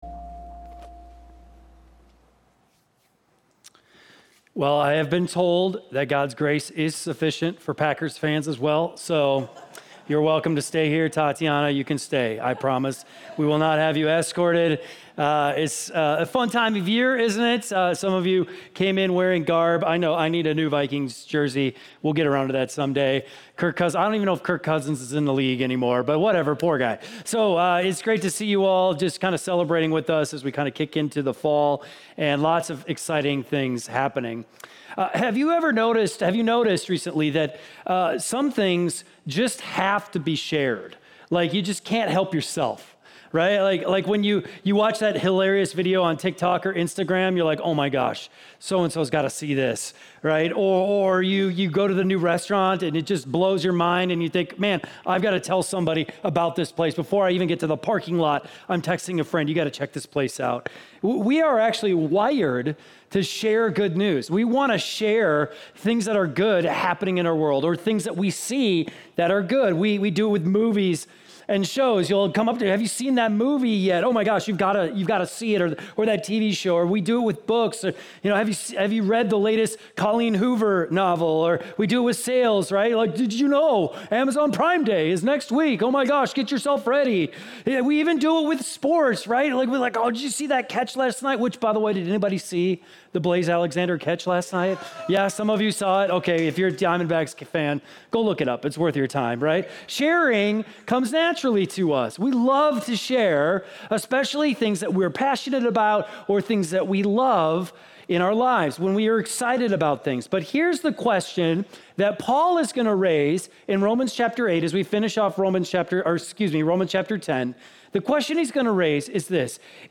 keyboard_arrow_left Sermons / Romans Series Download MP3